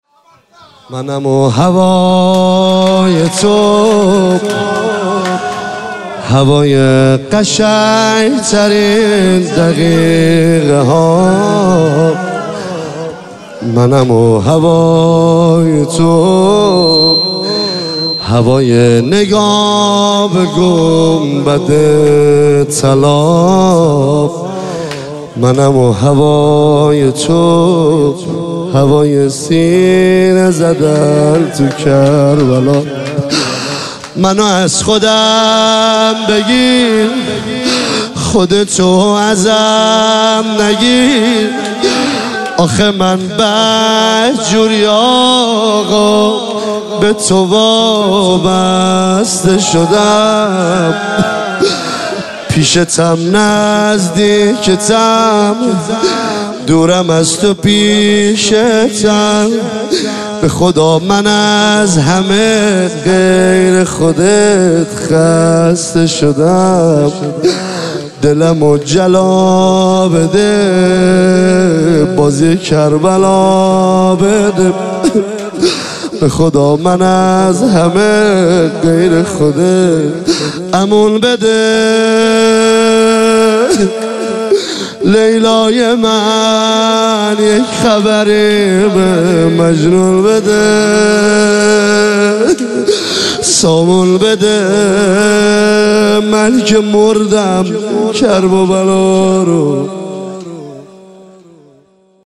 مراسم ایام فاطمیه دوم
زمزمه